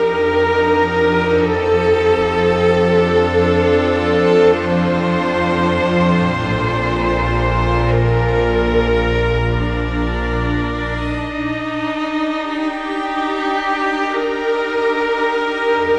Rock-Pop 17 Strings 01.wav